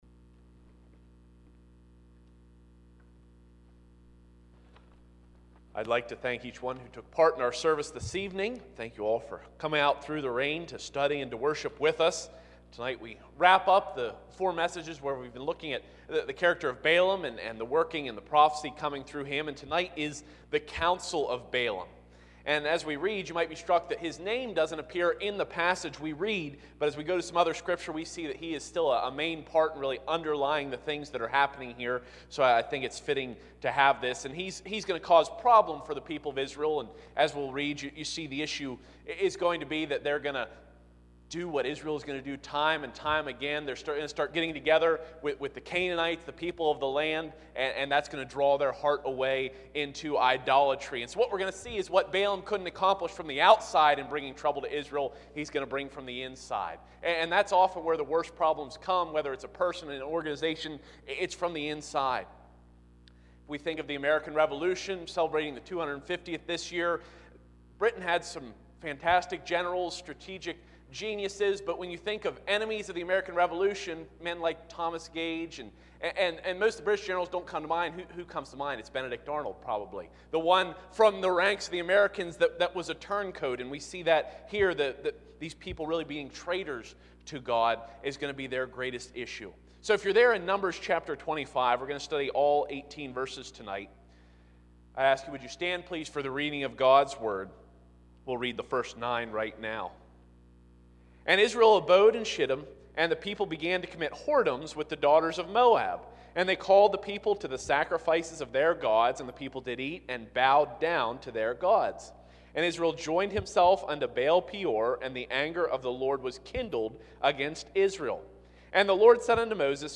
Numbers 25:1-18 Service Type: Sunday 6:00PM I. The Wickedness of Baal-Peor v. 1-9 II.